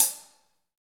HH HH 3007R.wav